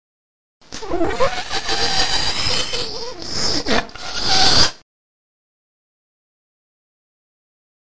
记录在Audacity，这是我的声音，但我扭曲了它。我也通过说话让声音听起来嘶哑来制造声音。
Tag: 怪物 恐怖 大脑 僵尸